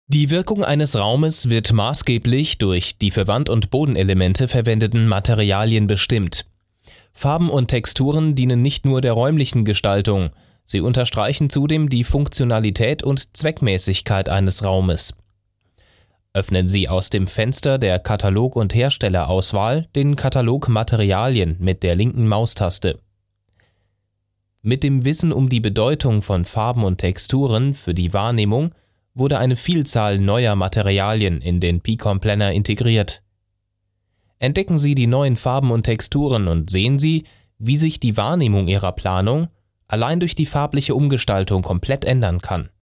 Sprecher deutsch. Stimme: krĂ€ftig, markant, erotisch, werblich
Sprechprobe: Werbung (Muttersprache):
german voice over talent